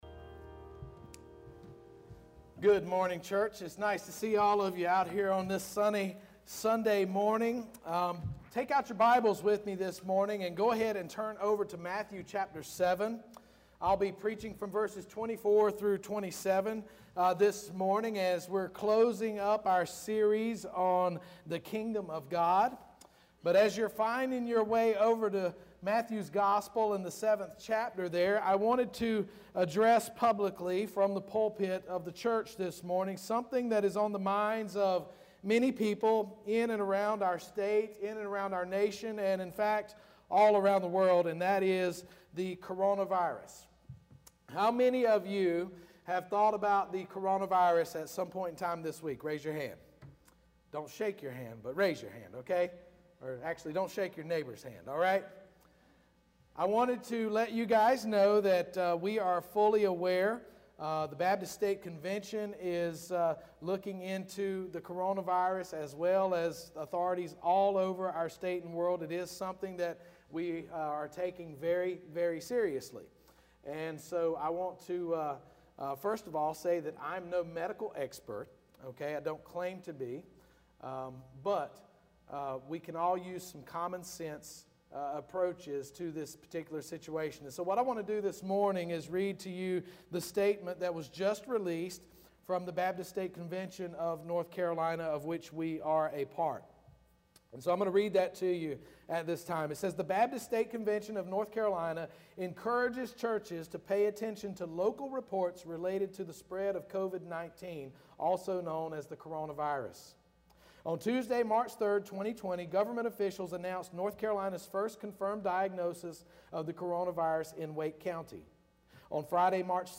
First Baptist Church Roanoke Rapids Online Sermons